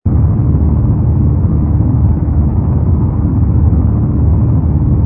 rumble_fighter.wav